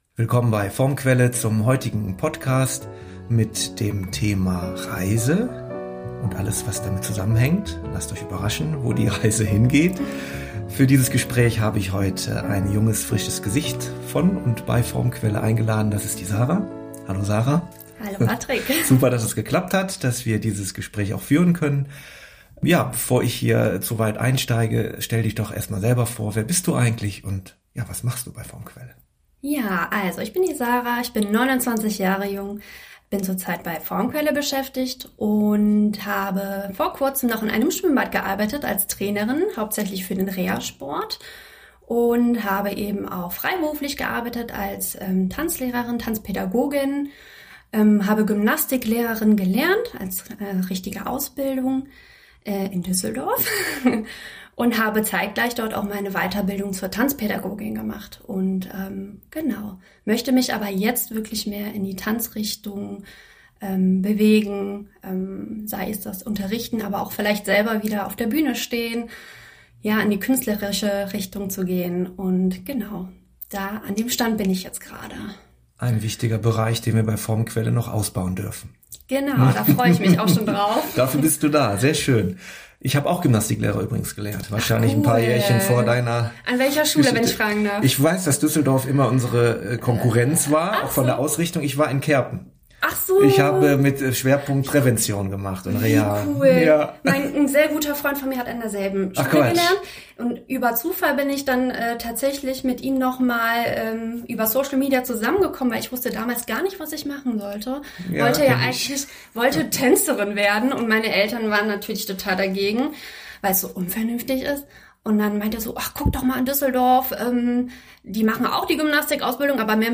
Heute widmen wir uns im Gespräch dem spannenden Thema der Reise, gerade in diesen Zeiten. Dies gilt sowohl für die äußere wie auch die innere Variante..